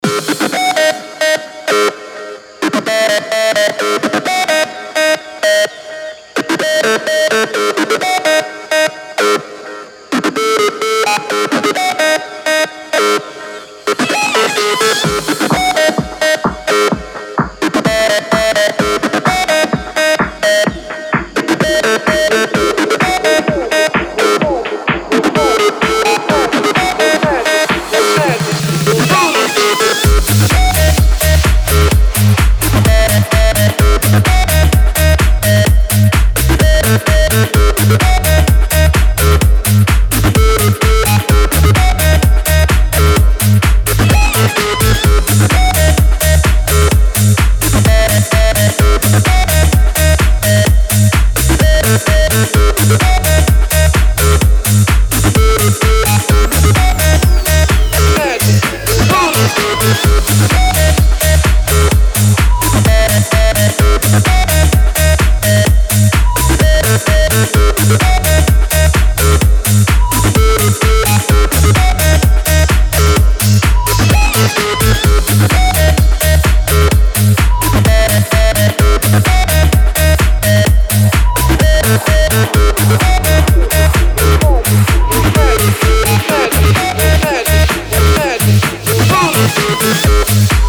• Качество: 320, Stereo
ритмичные
громкие
веселые
Ритмичный, бодрый рингтончик на старый, всем известный мотив